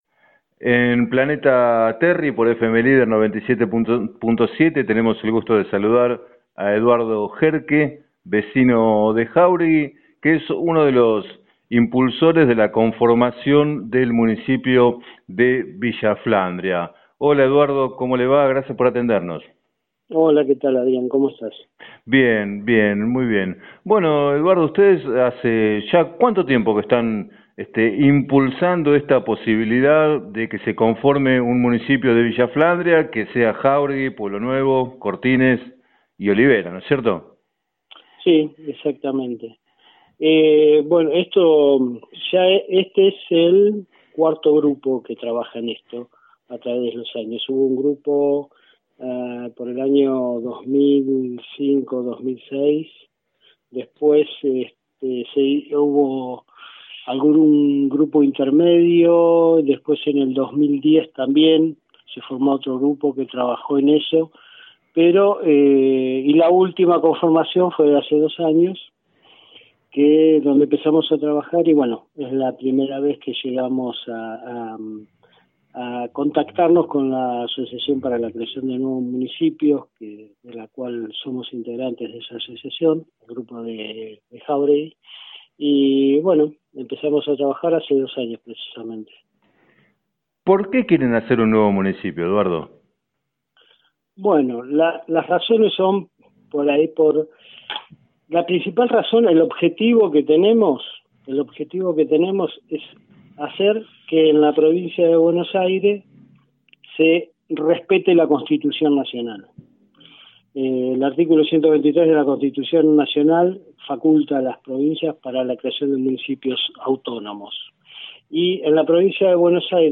En declaraciones al programa Planeta Terri de FM Líder 97.7